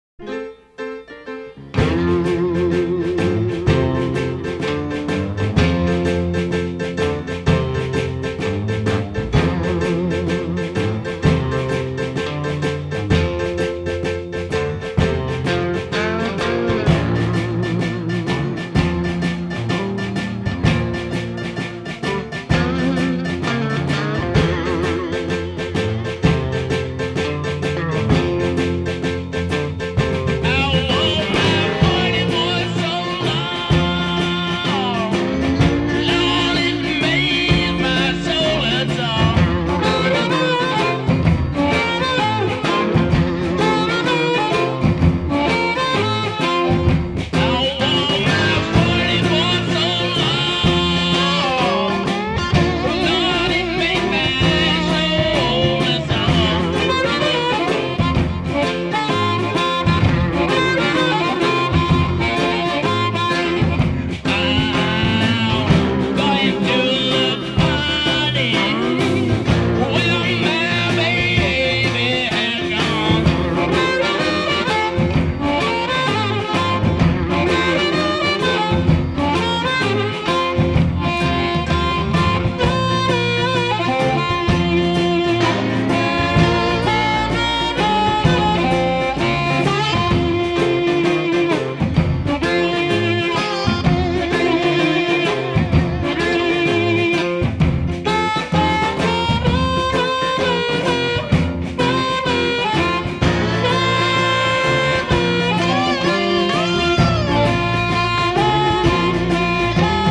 Allmusic.comAMG）五星滿點推薦，藍味十足的南方搖滾經典之作！